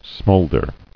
[smoul·der]